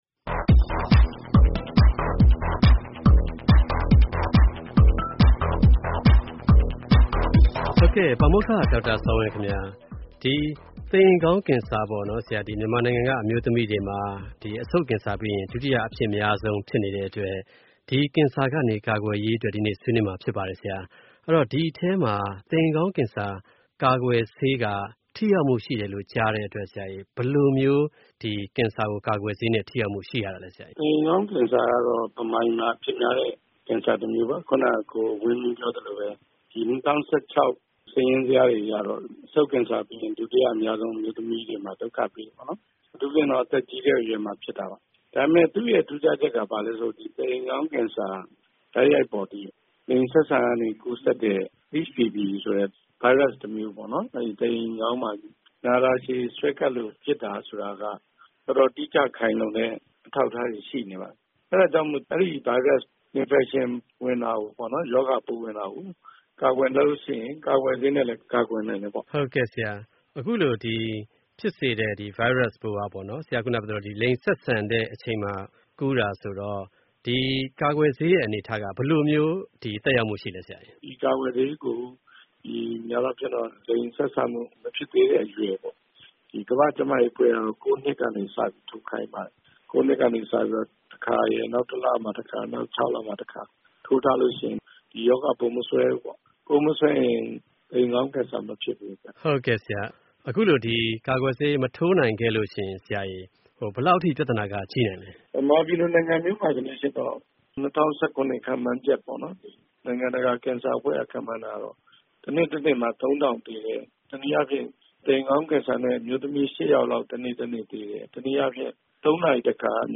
ဆက်သွယ်မေးမြန်း ဆွေးနွေးတင်ပြထားပါတယ်။